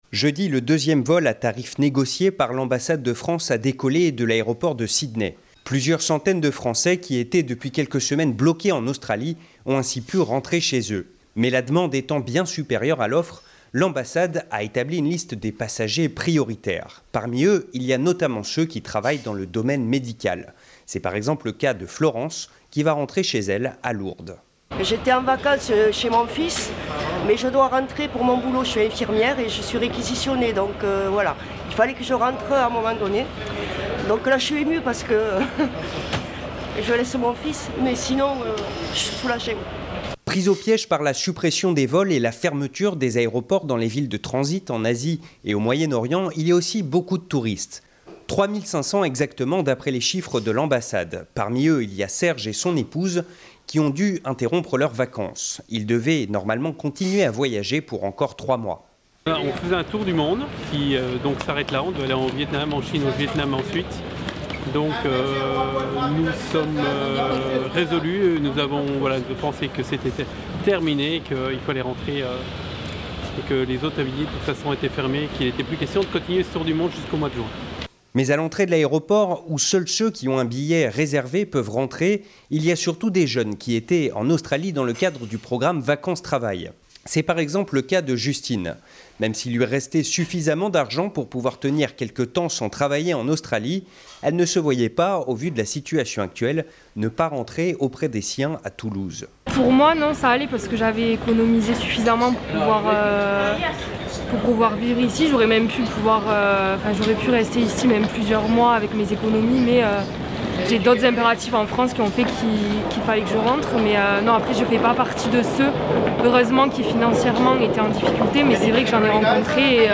Un deuxième vol à tarif négocié par l'ambassade de France avec Qatar Airways a décollé hier depuis Sydney. Nous étions sur place, pour interroger les Français qui ont pu monter à bord...